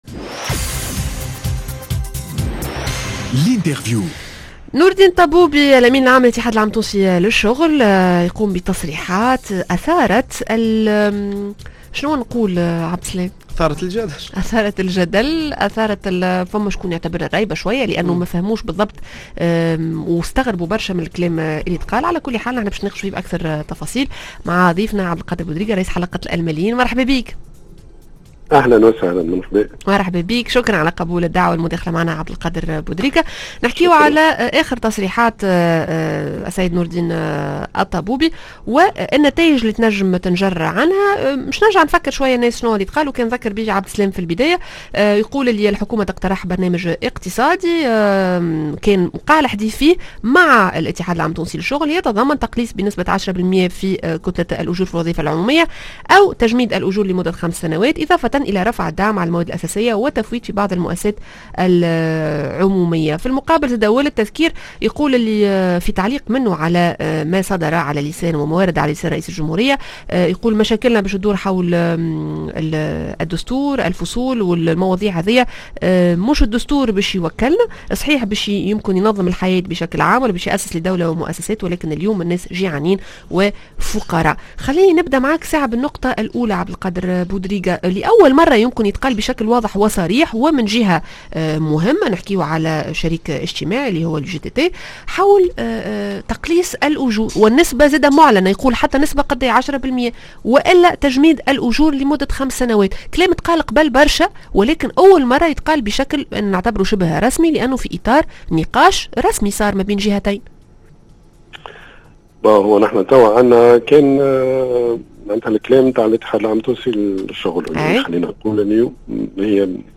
L_interview: بعد تصريحات نور الدين الطبوبي هل نخشى أزمة أكبر؟